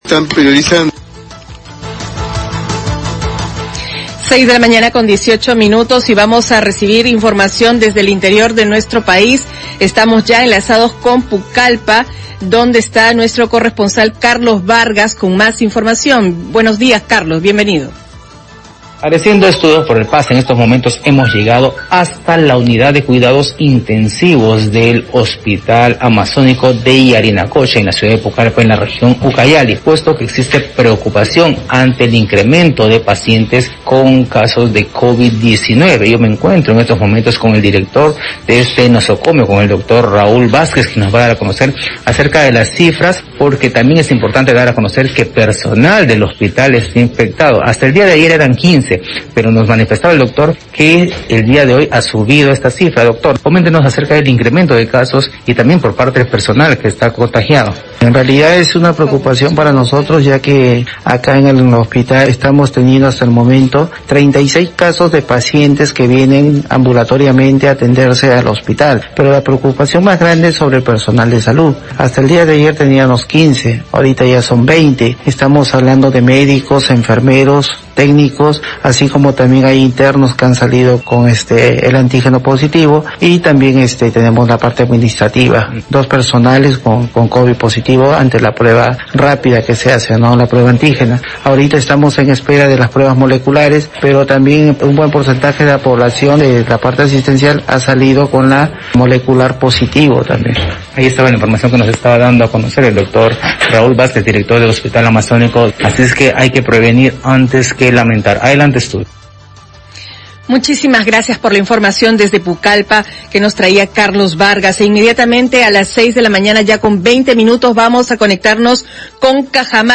Reporte de Pucallpa